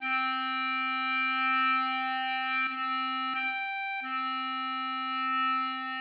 play on clarinets of the Bohlen–Pierce scale (3:1).
Tritave_on_C_clarinet.mid.mp3